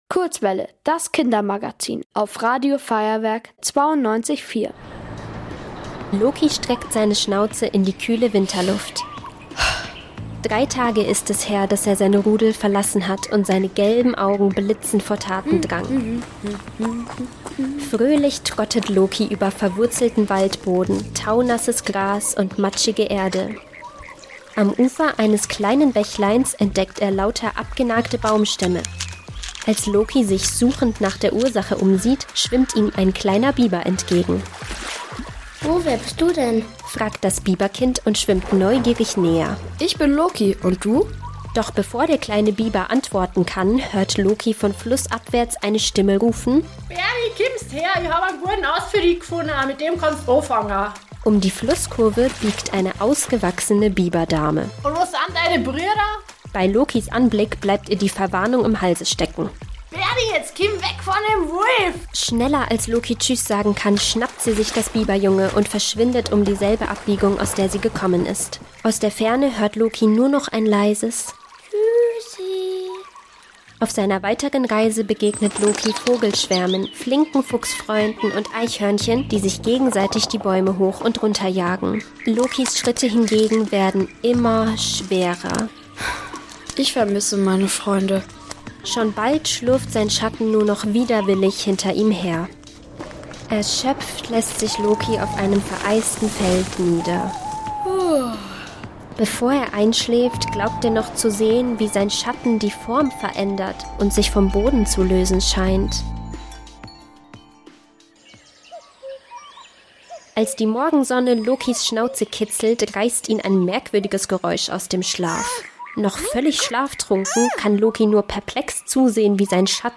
Hörspiel: Sammy, die Einsamkeit